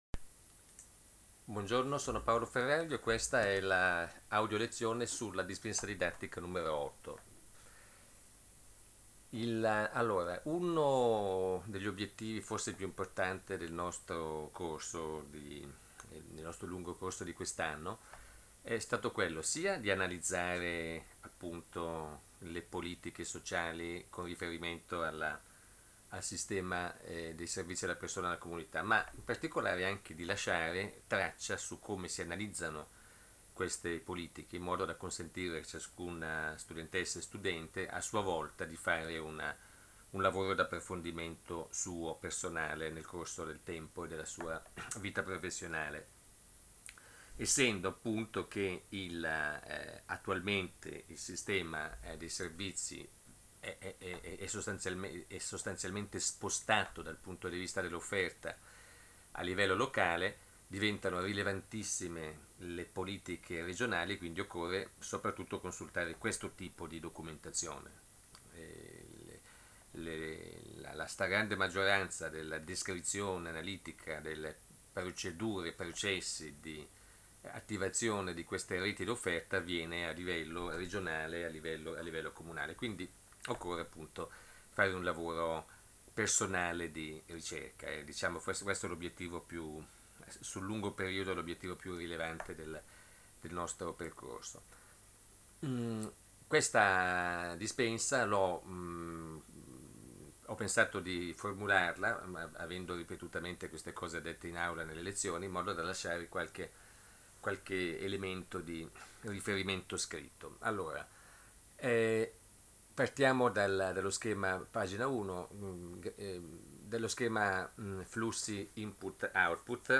AUDIO LEZIONE su ANALISI DELLE RIFORME: METODI E TECNICHE